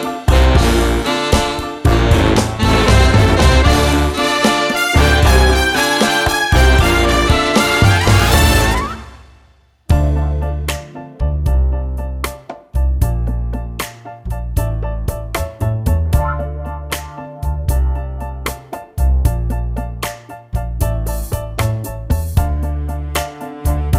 One Semitone Down Jazz / Swing 3:54 Buy £1.50